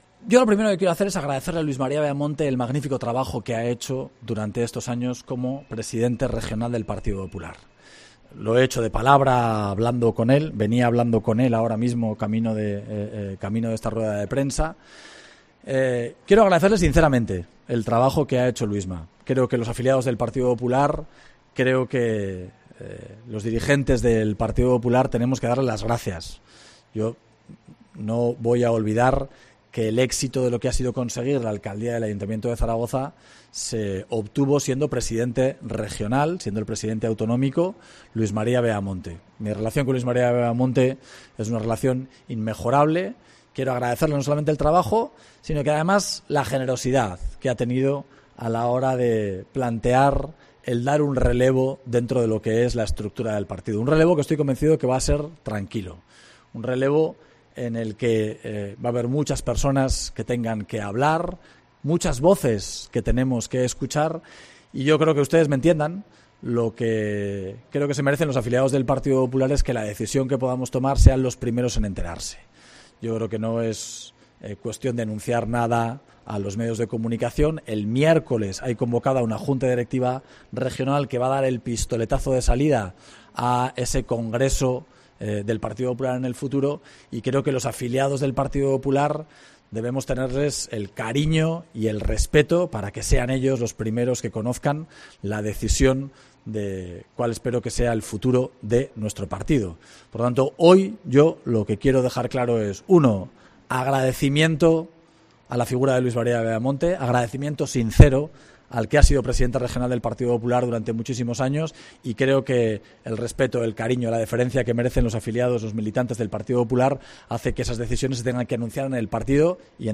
El alcalde de Zaragoza, Jorge Azcón, habla de su futuro en el PP de Aragón.